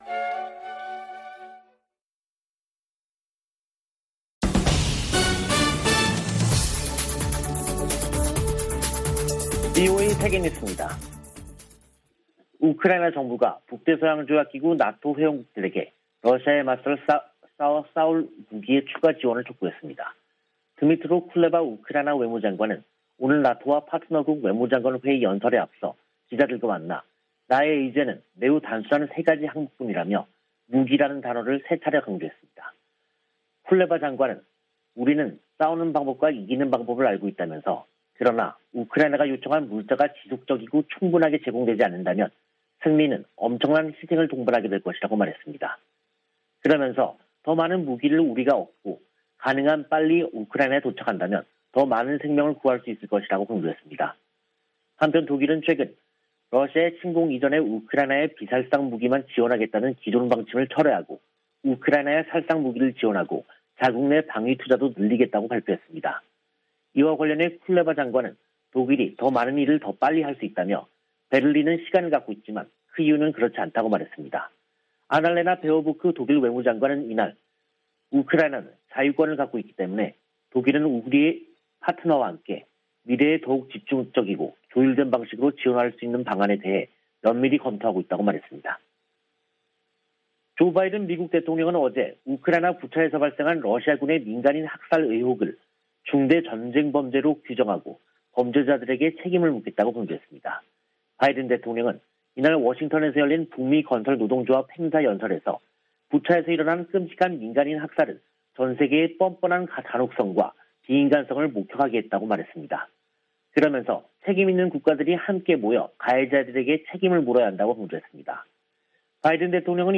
VOA 한국어 간판 뉴스 프로그램 '뉴스 투데이', 2022년 4월 7일 2부 방송입니다. 웬디 셔먼 미 국무부 부장관은 핵무장한 북한은 중국의 이익에도 부합하지 않는다며, 방지하기 위한 중국의 협력을 촉구했습니다. 미 국방부는 한국 내 전략자산 배치와 관련해 한국과 협력할 것이라고 밝혔습니다. 윤석열 한국 대통령 당선인이 캠프 험프리스를 방문해 북한의 핵과 미사일 위협에 강력 대응 의지를 밝혔습니다.